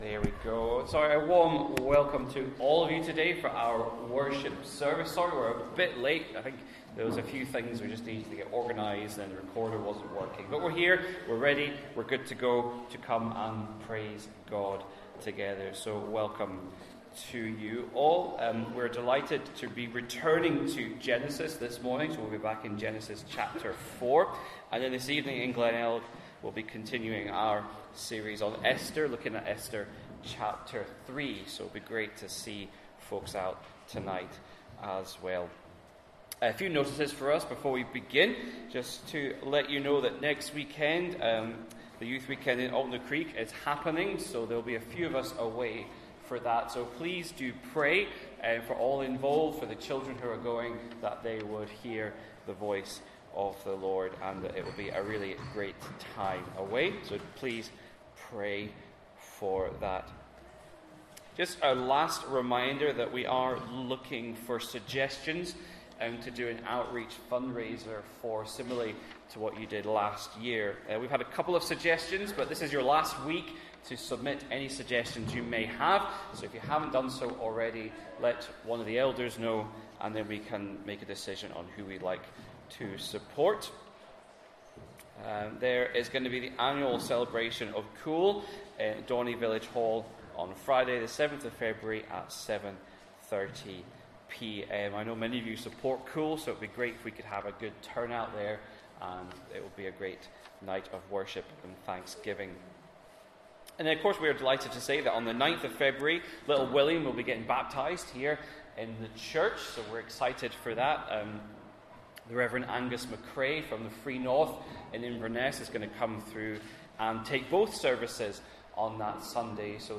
12 Noon Sevice